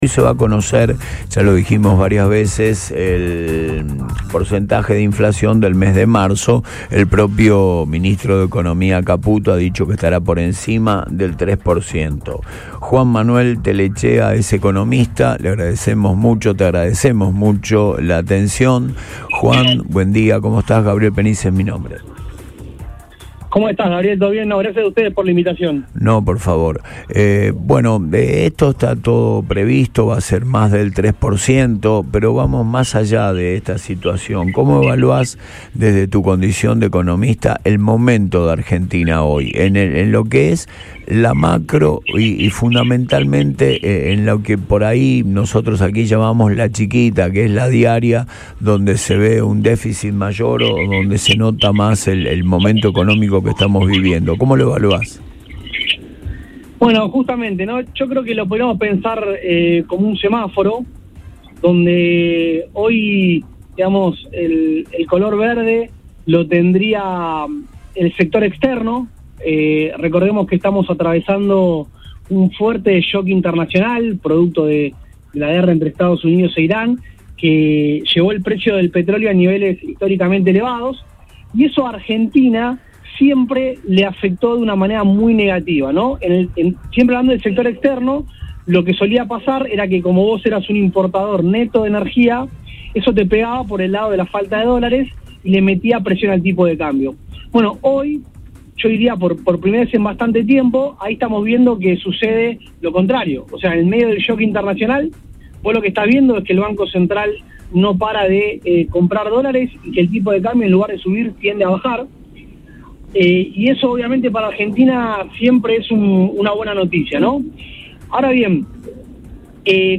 En diálogo con el programa Antes de Todo por Radio Boing, el economista